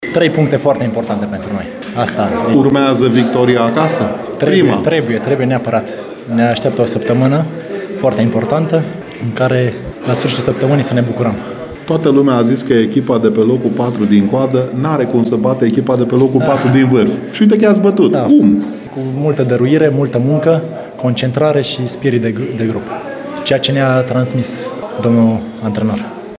Într-un scurt dialog